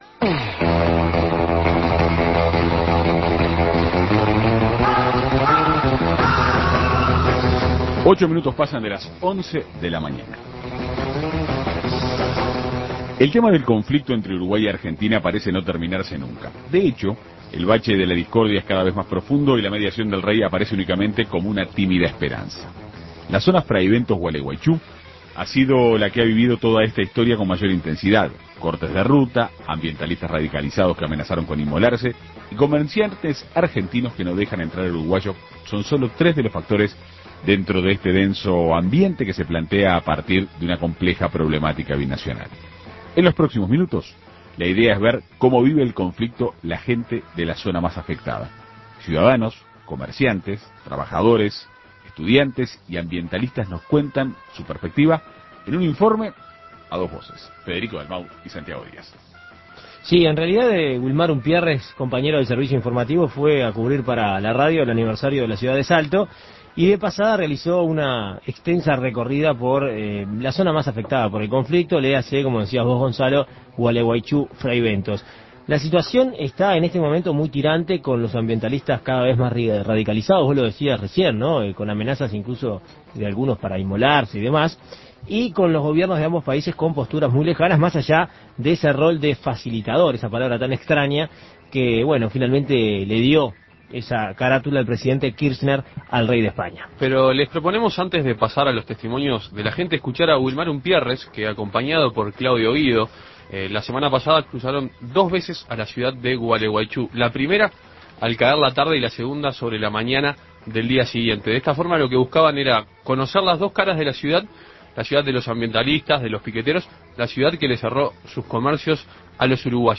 Informes Cómo viven el conflicto de las papeleras los vecinos de Fray Bentos y Gualeguaychú.